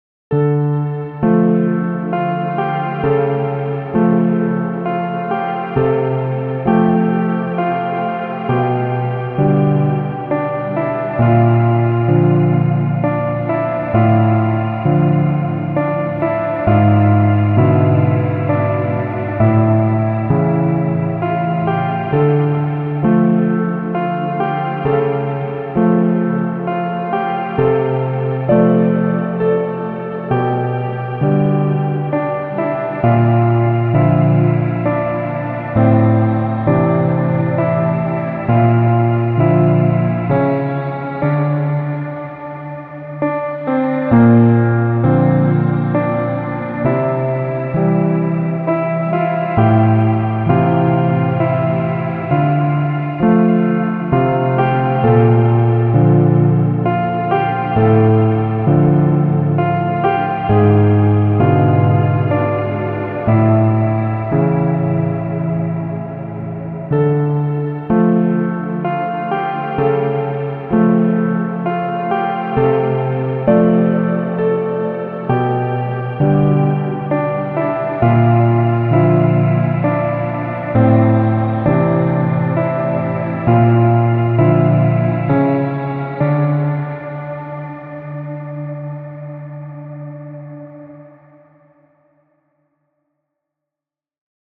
暗く切ない神秘的でゆったりとしたピアノソロ曲です。
テンポ ♩=free
ピアノバージョン ダウンロード